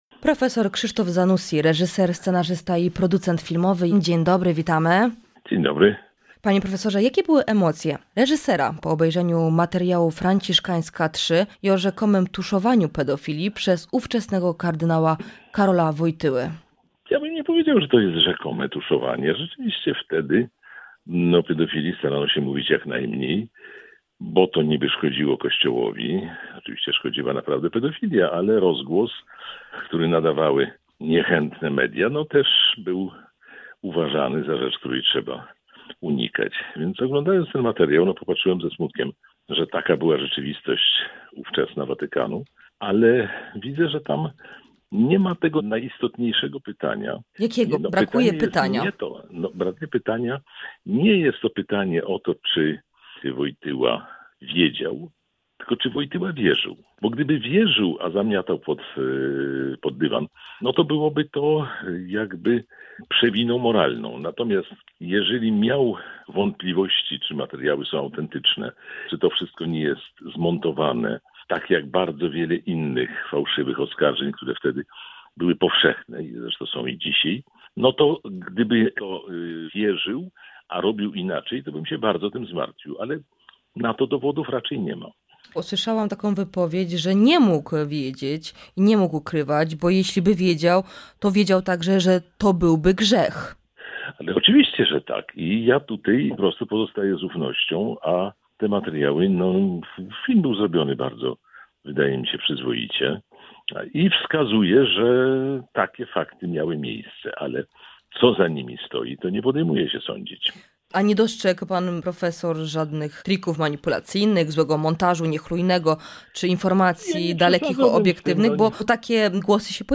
Prof. Krzysztof Zanussi, reżyser, scenarzysta i producent filmowy był naszym „Porannym Gościem”. W rozmowie poruszyliśmy sprawę materiału "Franciszkańska 3" o tuszowaniu pedofilii przez ówczesnego kardynała Karola Wojtyłę.